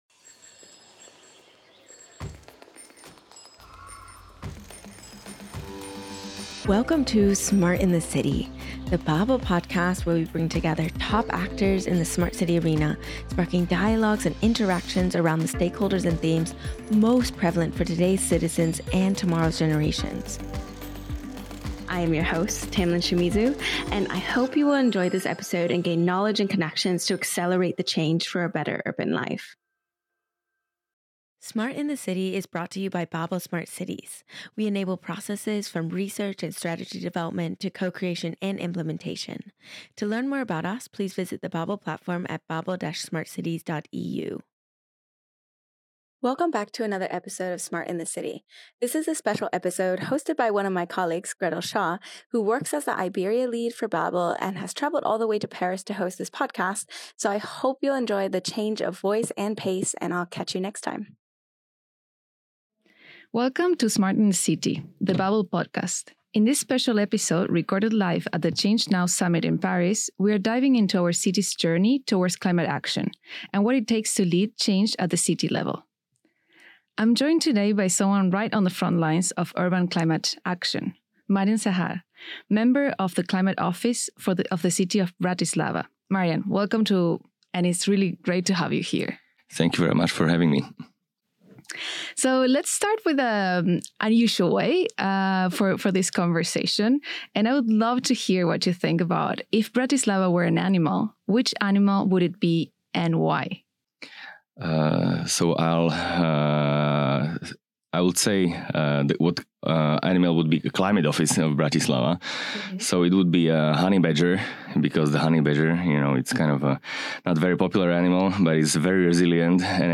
recorded live at the Change Now Summit in Paris